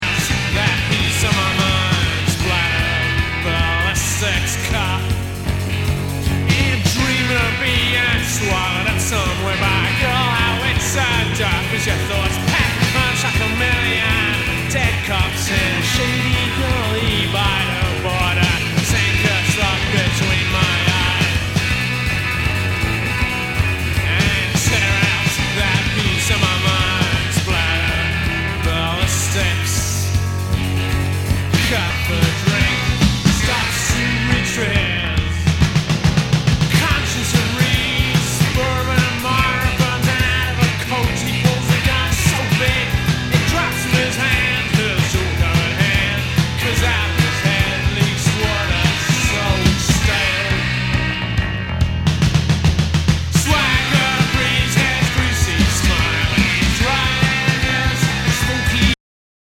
ROCK/POPS/INDIE
全体にチリノイズが入ります